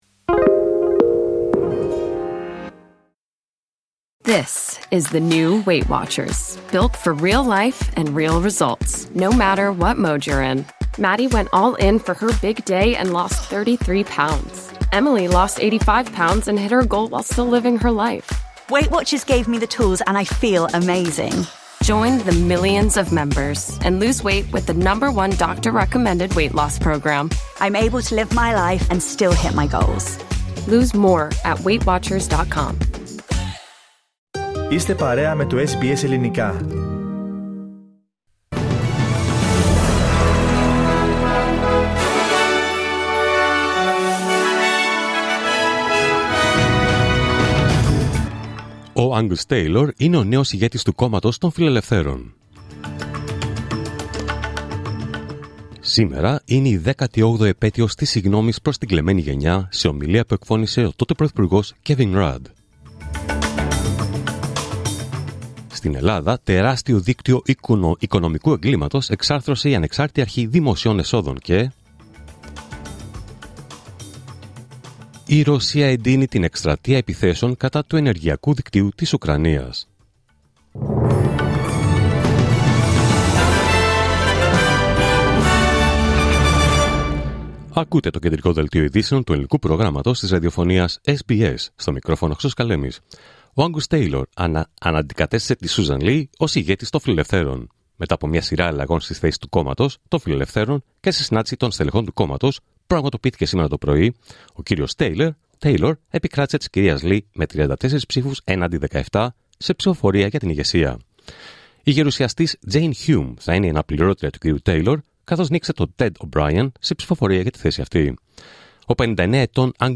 Δελτίο Ειδήσεων Παρασκευή 13 Φεβρουαρίου 2026